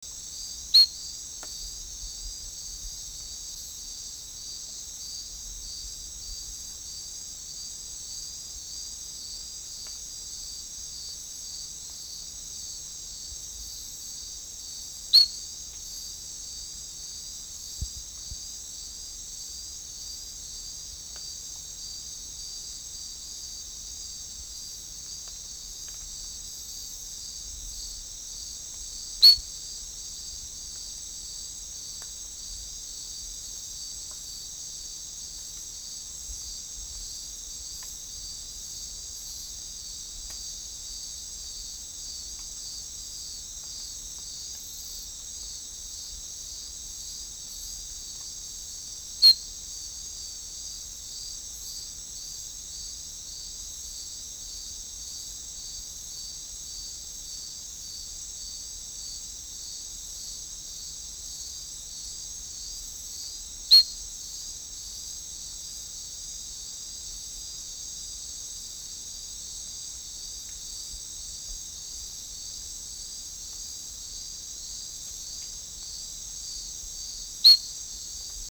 Especie: Hyalinobatrachium fleischmanni
Órden: Anura
Localidad: México
Hyalinobatrachium fleischmanni 1 indiv.mp3